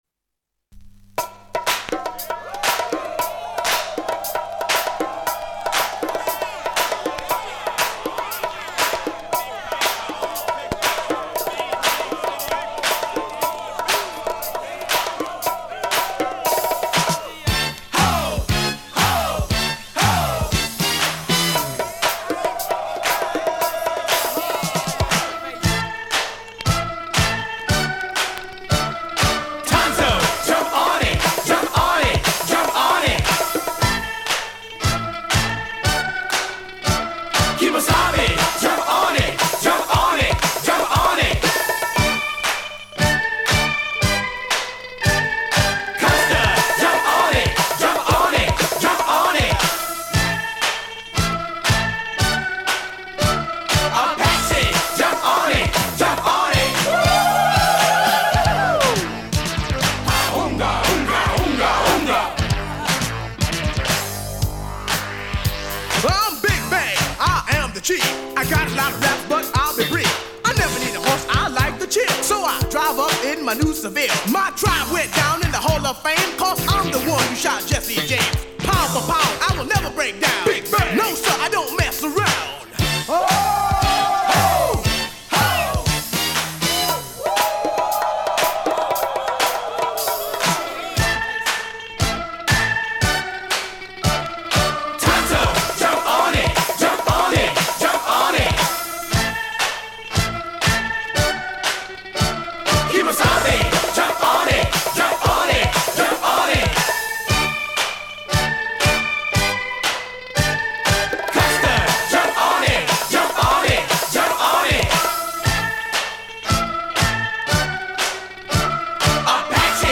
SESSION RAP FUNK 80’s